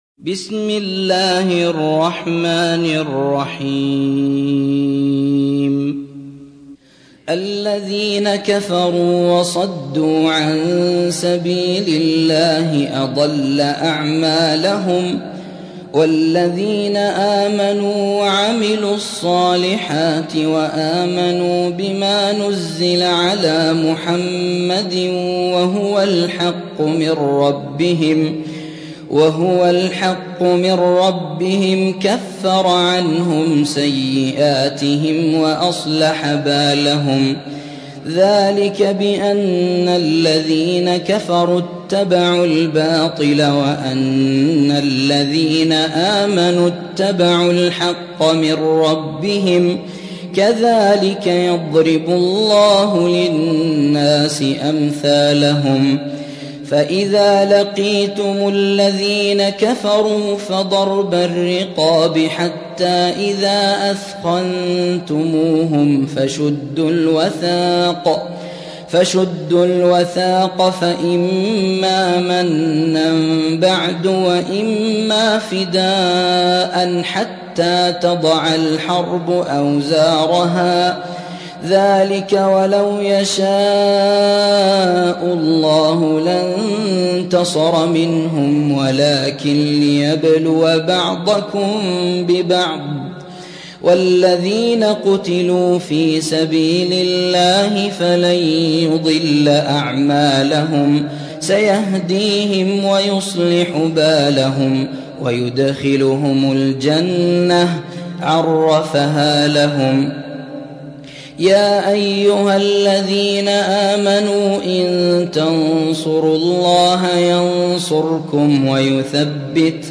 47. سورة محمد / القارئ